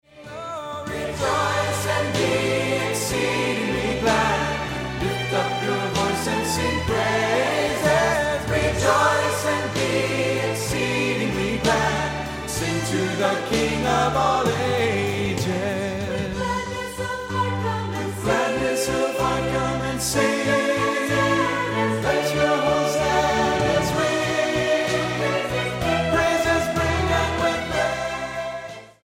STYLE: Orchestral